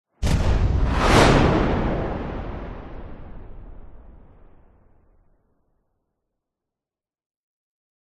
На этой странице собраны звуки ударной волны после взрыва — от глухих ударов до резких перепадов давления.
Грохот мощной ударной волны